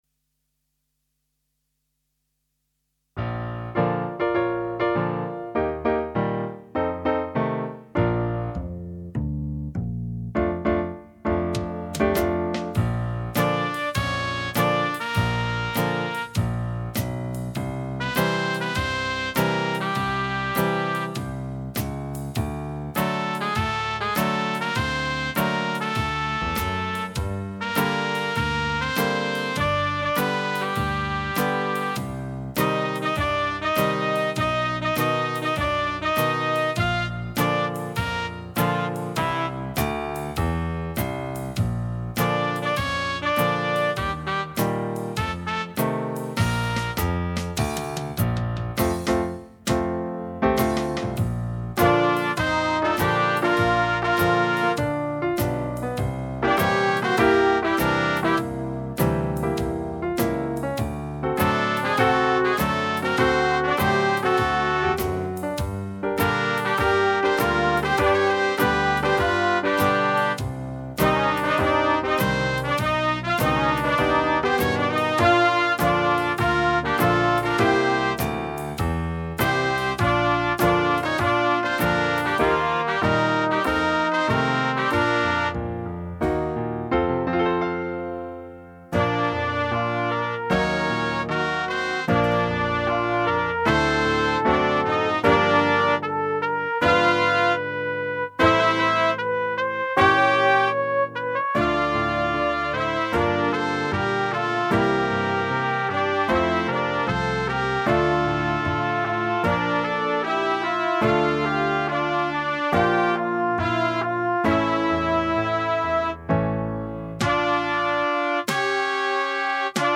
Instrumentation:C, Bb, Eb, Rhythm Gtr, Bass
An accessible blues piece for small / medium ensemble.
Standard chord progressions allow for improvisation